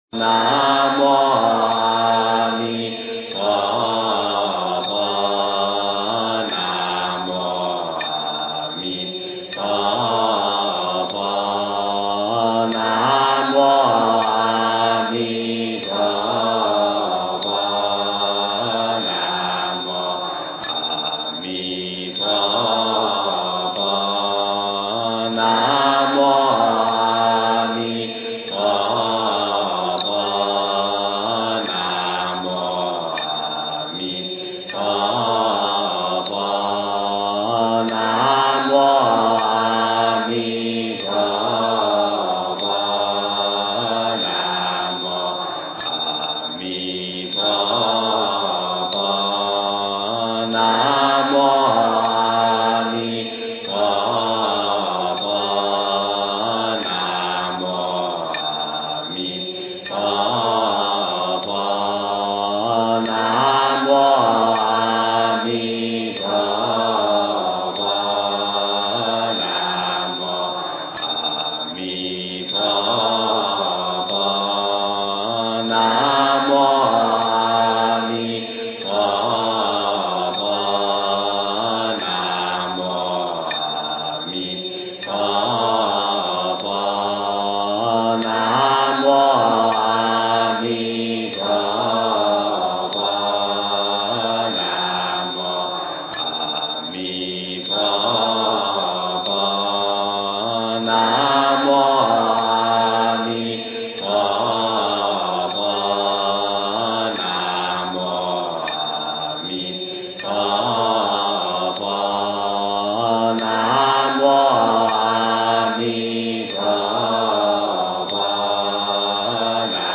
诵经
佛音 诵经 佛教音乐 返回列表 上一篇： 解毒神咒 下一篇： 绿度母心咒 相关文章 楞严经01--梦参法师 楞严经01--梦参法师...